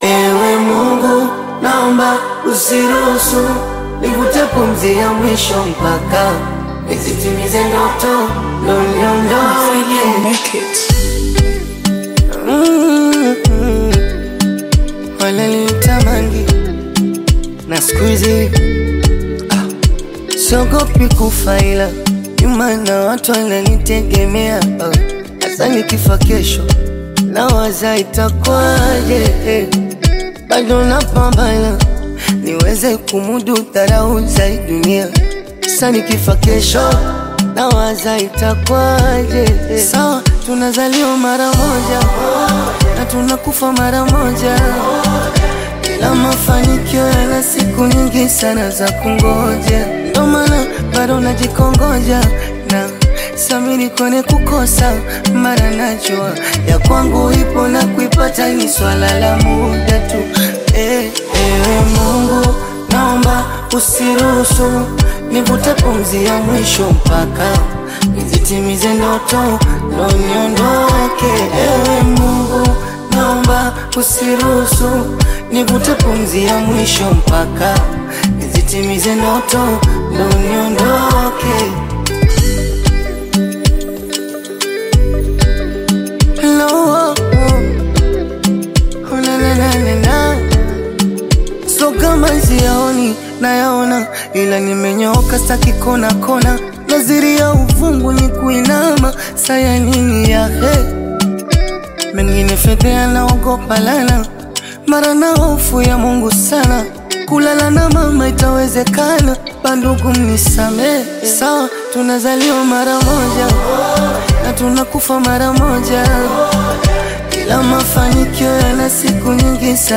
emotional new single
touching Bongo Flava melodies with heartfelt lyrics
With its emotional message and soulful production